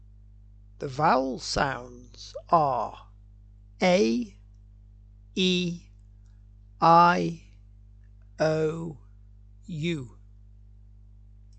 Vowel-sounds-a-e-i-o-u.mp3